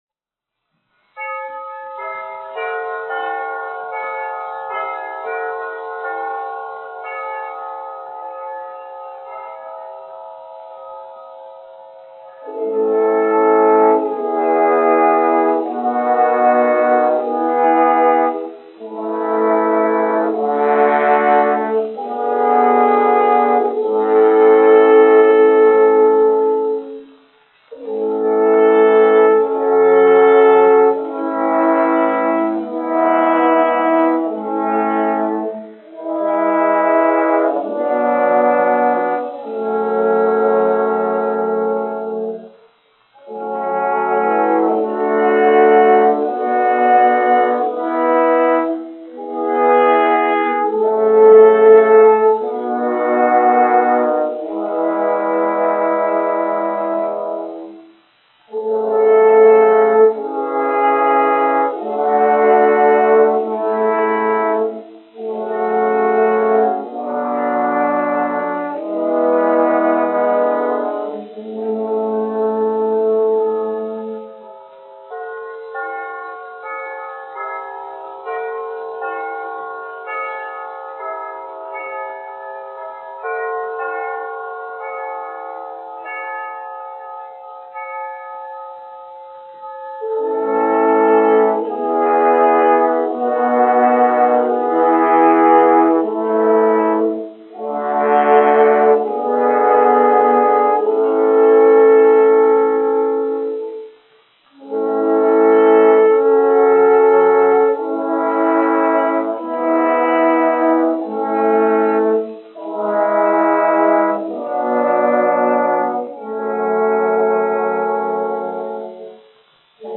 1 skpl. : analogs, 78 apgr/min, mono ; 25 cm
Ziemassvētku mūzika
Metāla pūšaminstrumentu kvarteti (tromboni (4))
Latvijas vēsturiskie šellaka skaņuplašu ieraksti (Kolekcija)